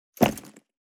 437物を置く,バックを置く,荷物を置く,トン,コト,ドサ,ストン,ガチャ,ポン,タン,スッ,ゴト,カチャ,
効果音室内物を置く